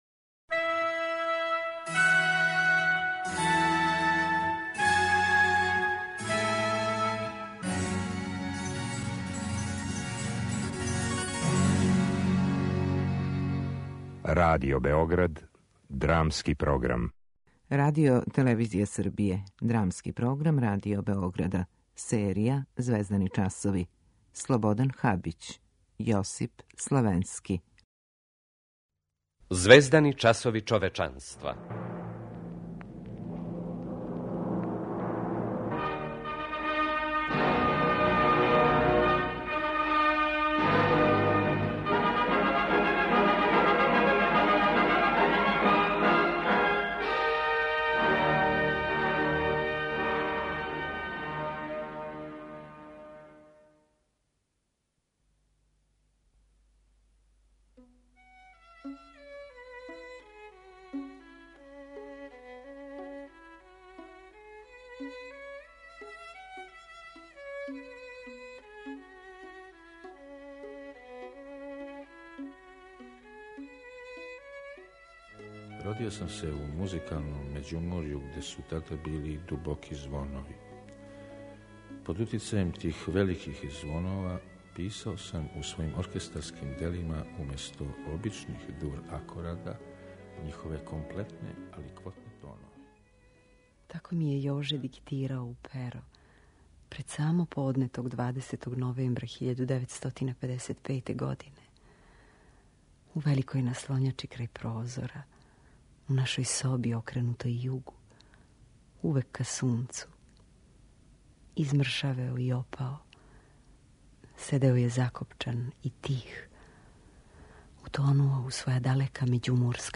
Драмски програм: Звездани часови
drama.mp3